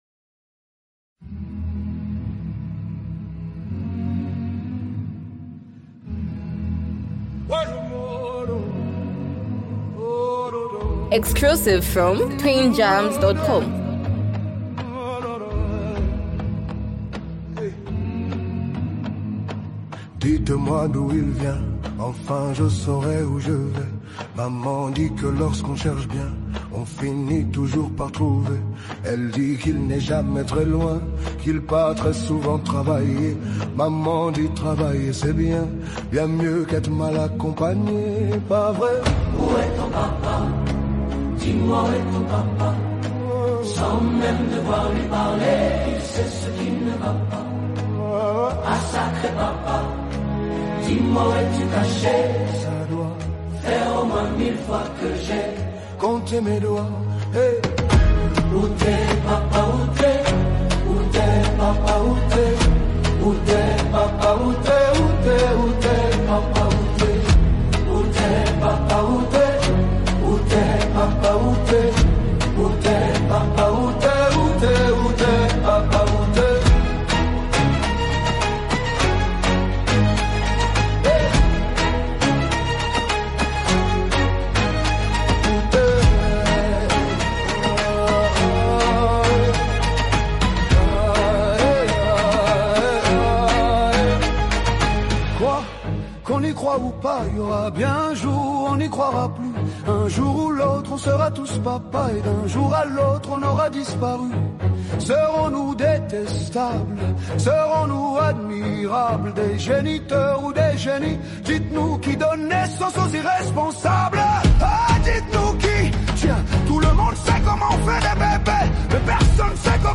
In this Afro Soul version
Through rich vocals and expressive delivery